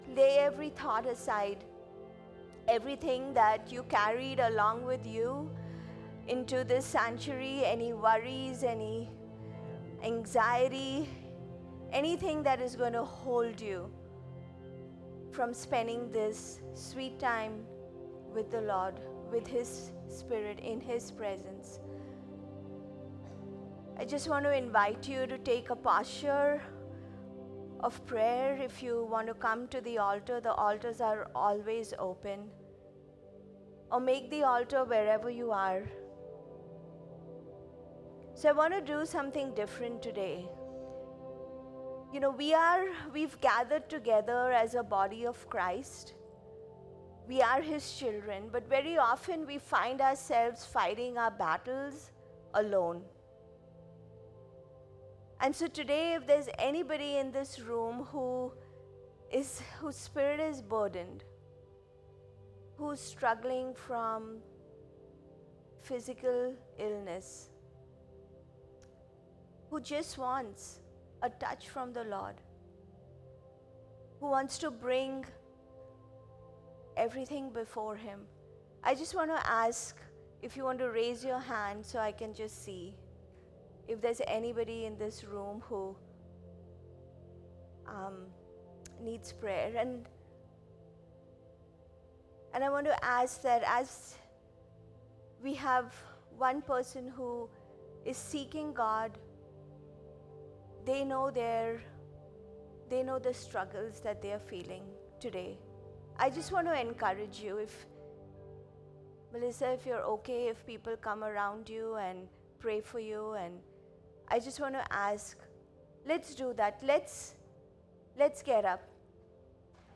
August 24th, 2025 - Sunday Service - Wasilla Lake Church